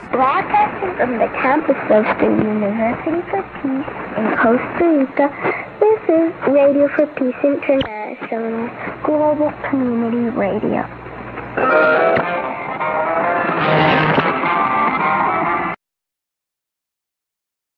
Another station identification of RFPI